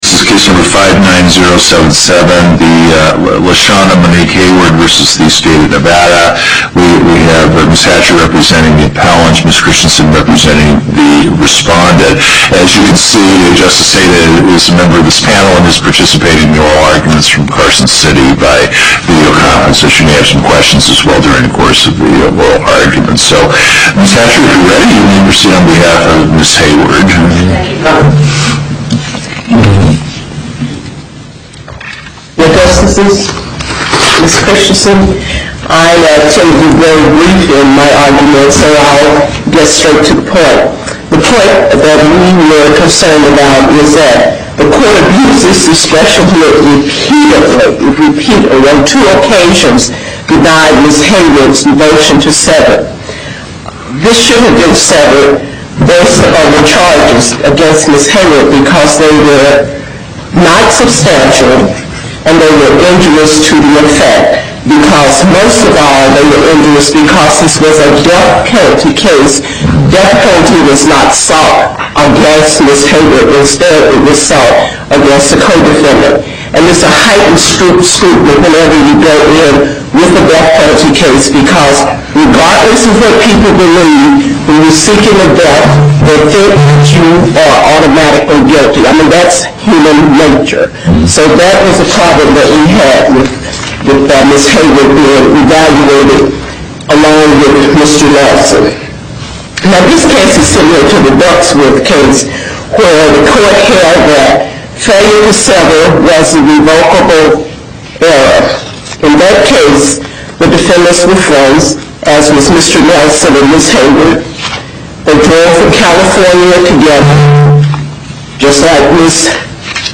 Location: Las Vegas Before the Southern Nevada Panel, Justice Gibbons Presiding